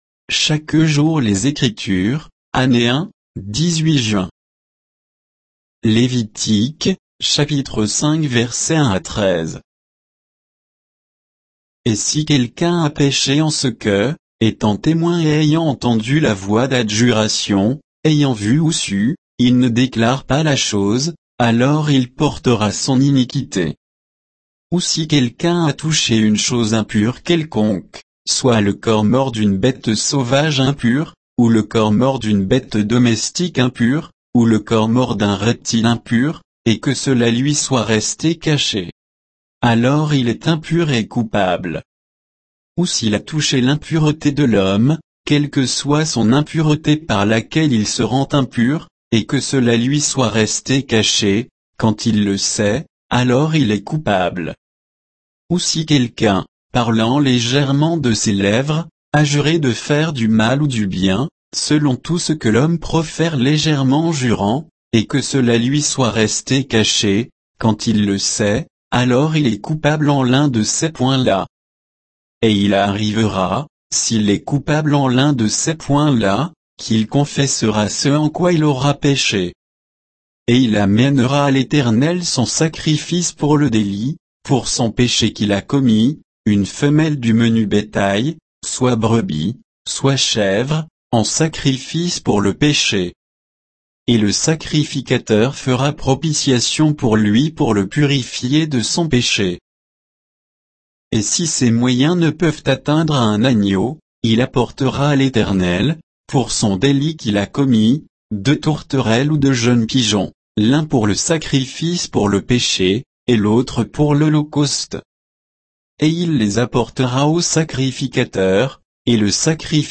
Méditation quoditienne de Chaque jour les Écritures sur Lévitique 5